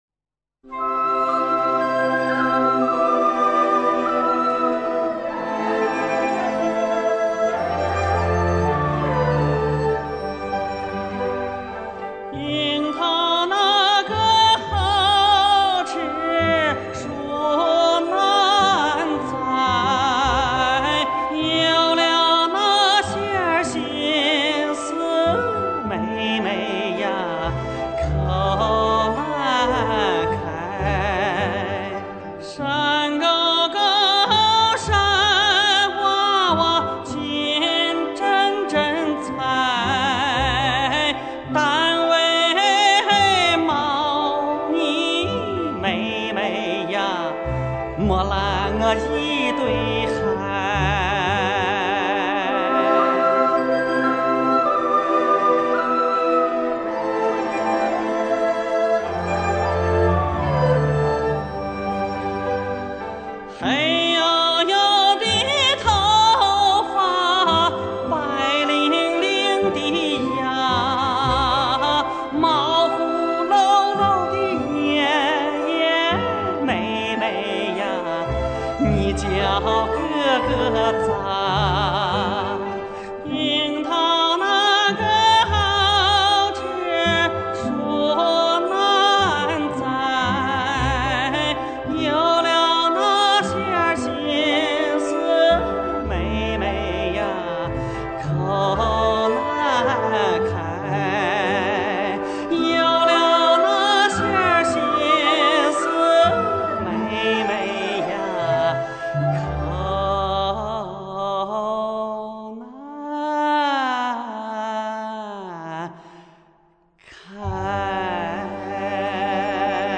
本套CD节目，不仅仅是原生态的民歌，且经过一定的编配，在音效上更加丰富多彩了。
山西左权民歌
山西平遥民歌
河曲民歌